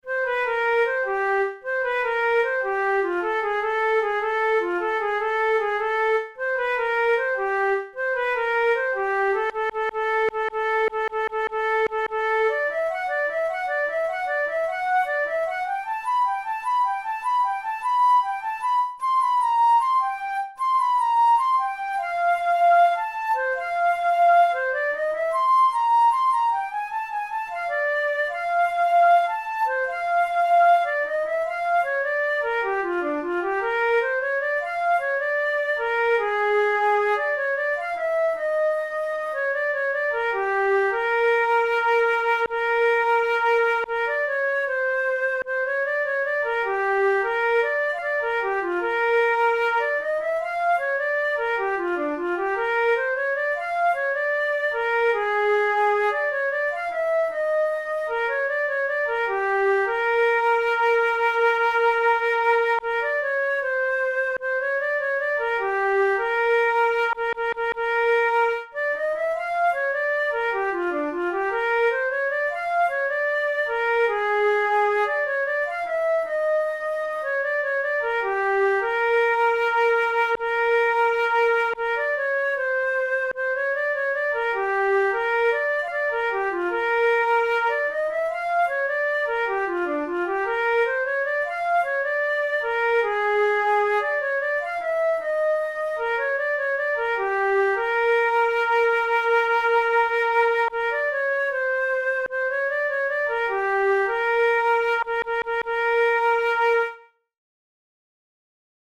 Jazz standard
Categories: Blues Jazz Difficulty: intermediate